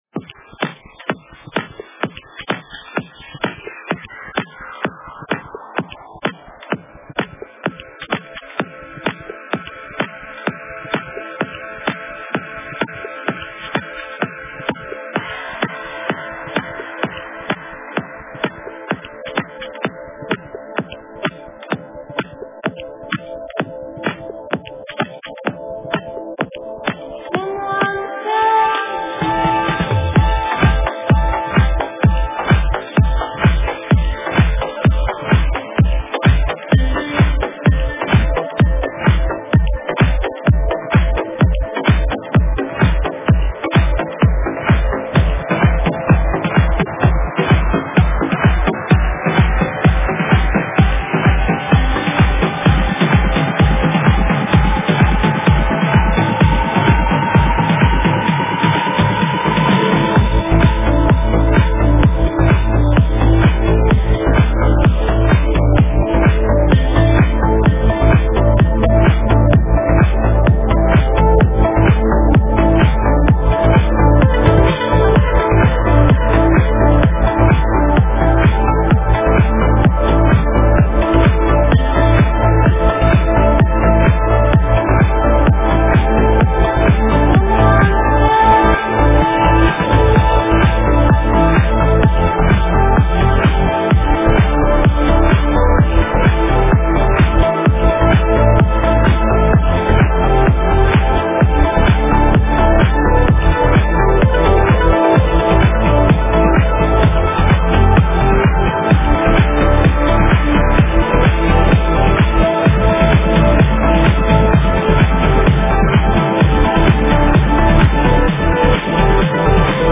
Стиль: Progressive House / Progressive Trance